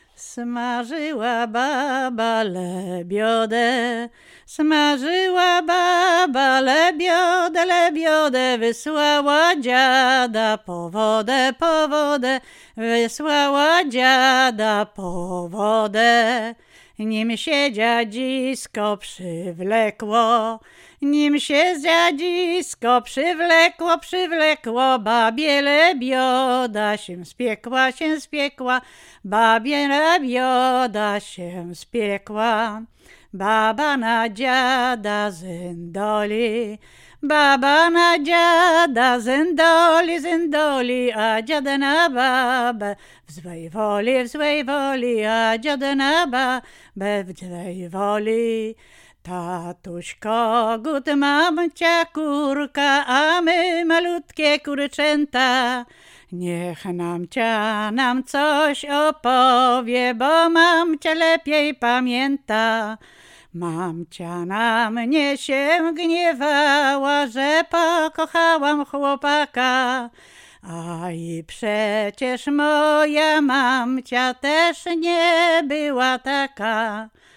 Łęczyckie
województwo łódzkie, powiat kutnowski, gmina Bedlno, wieś Bedlno
liryczne żartobliwe